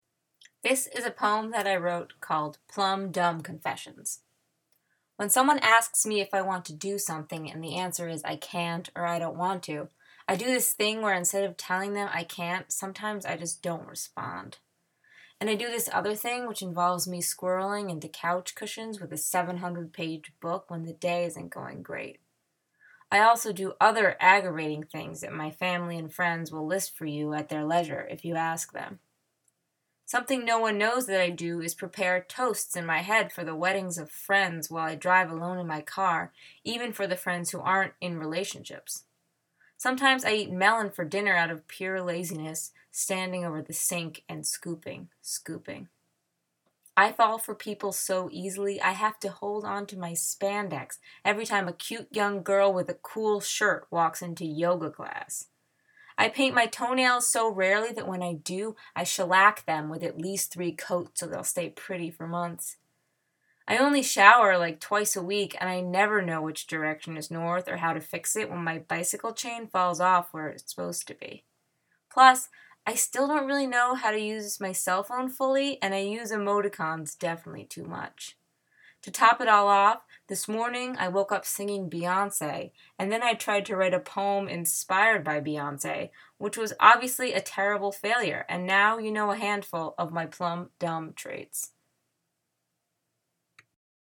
“Plumb Dumb Confessions” (poem out loud) (mine).